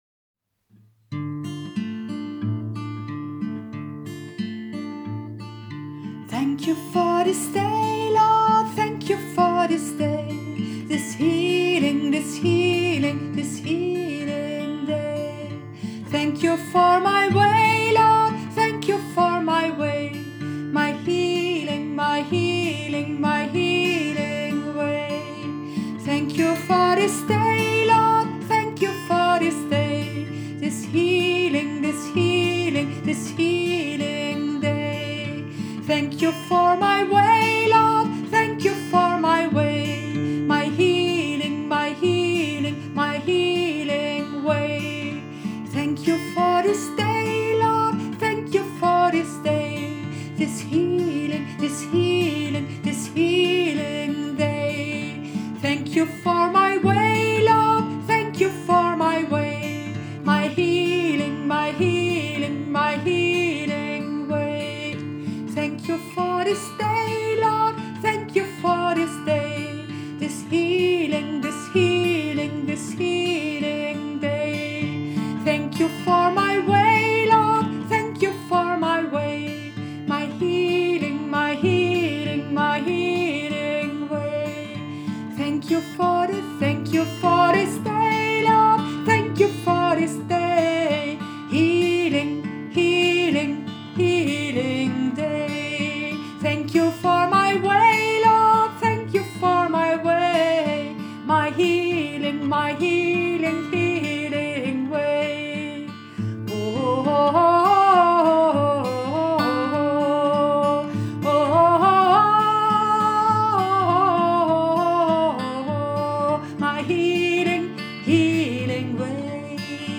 Thank you for this day Lord – Kanon zu 4 Stimmen